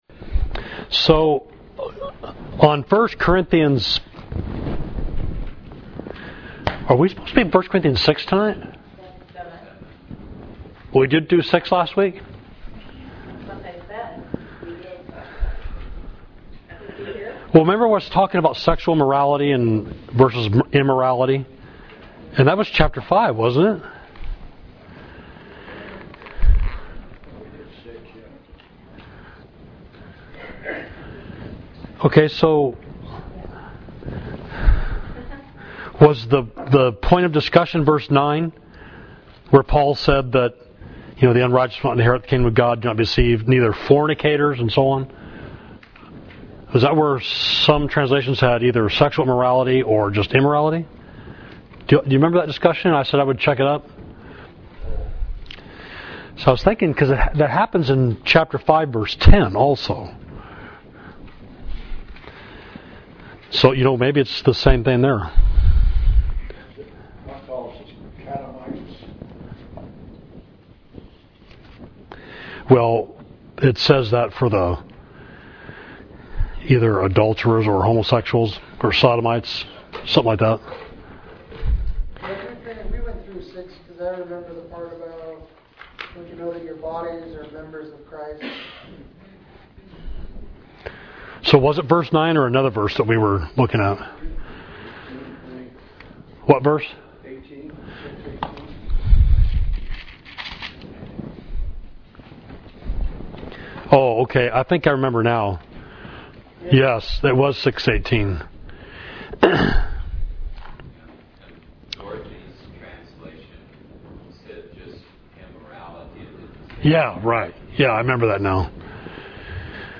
Class: First Corinthians 7 – Savage Street Church of Christ